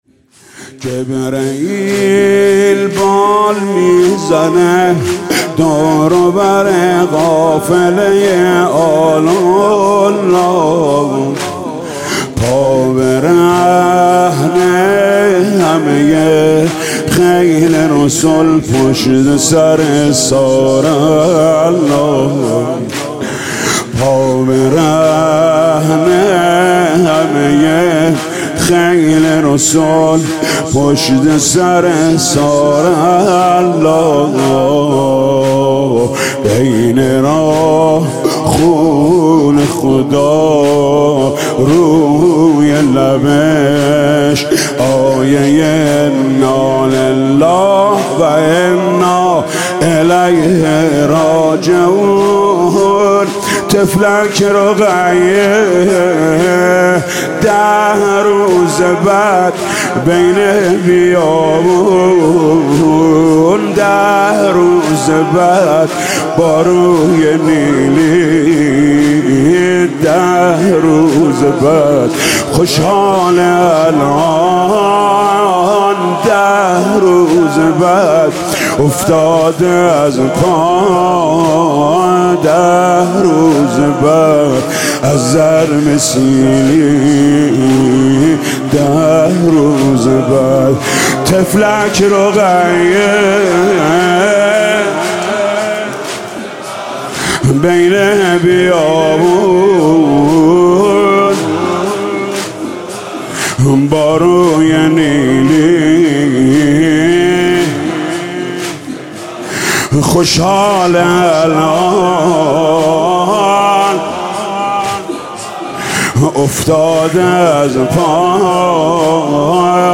گلچین نوحه و مداحی‌های شب دوم محرم